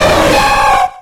Cri de Suicune dans Pokémon X et Y.